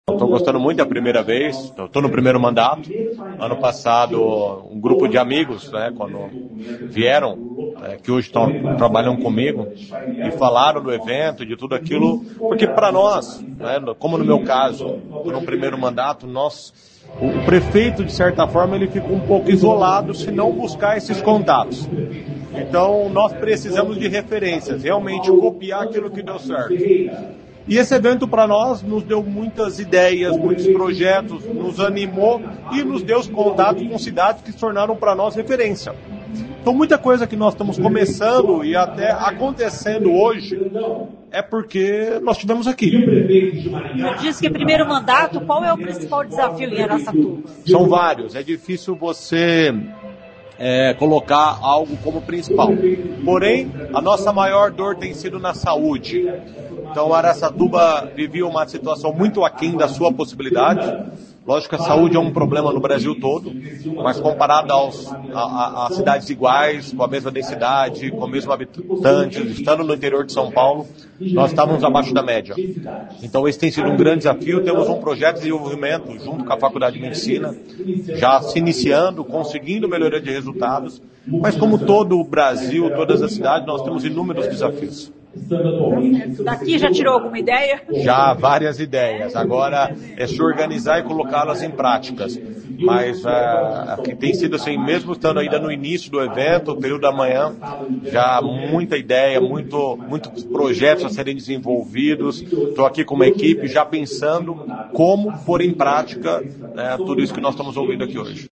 O prefeito de Araçatuba, interior de São Paulo, Lucas Zanatta, de primeiro mandato, veio a Maringá após ouvir boas referências sobre a primeira edição do Planeja Brasil.
A entrevista foi realizada no estúdio móvel CBN instalado no local do evento.